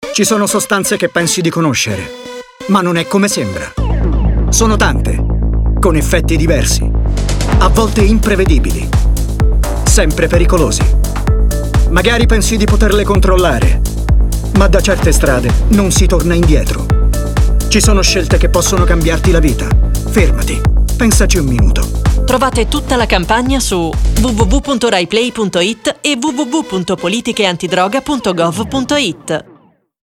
Lo spot radio
droga-radiofonico.mp3